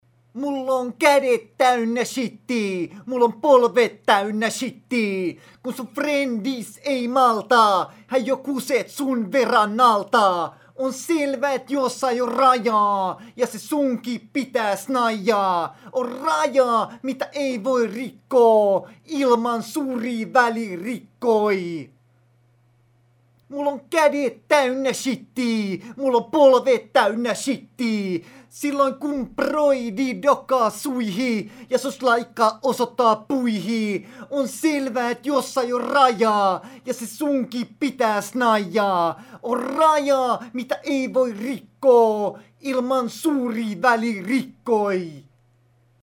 Hän on a cappella -lauluyhtye, jonka jäsenet ovat suurelta osin lahtelaistuneita.